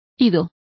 Also find out how idas is pronounced correctly.